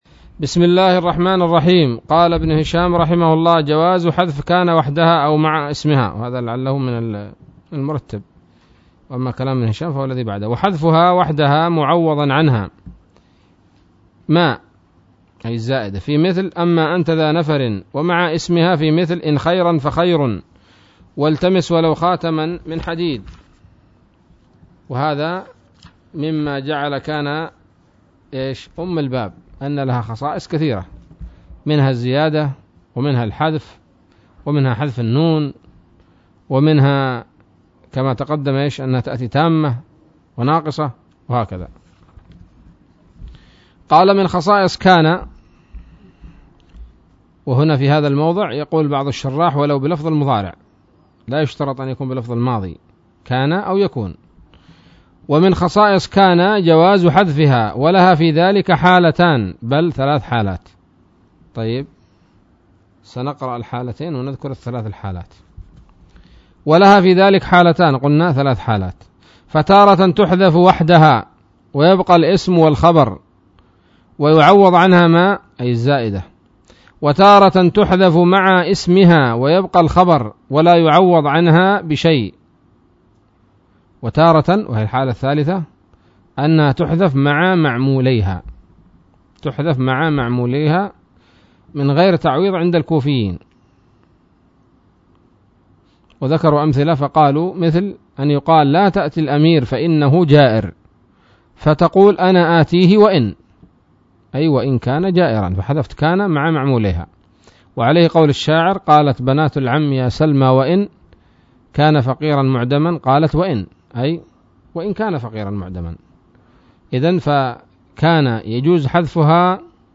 الدرس التاسع والخمسون من شرح قطر الندى وبل الصدى